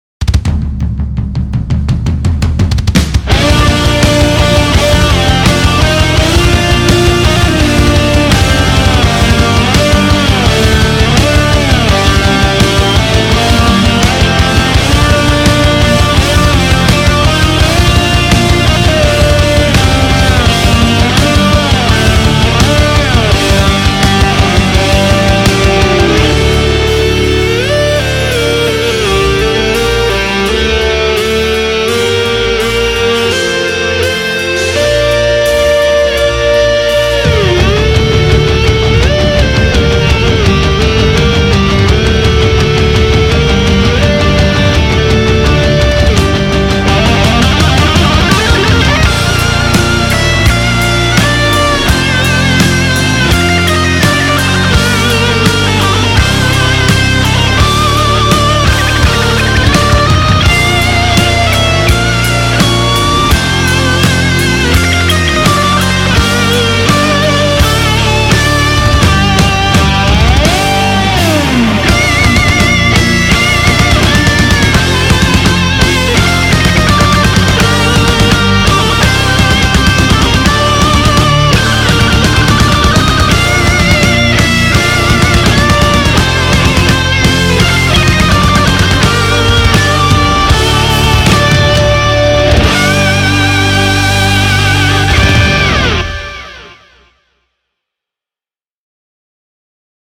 ГІМН_УКРАЇНИ___РОК_ВЕРСІЯ__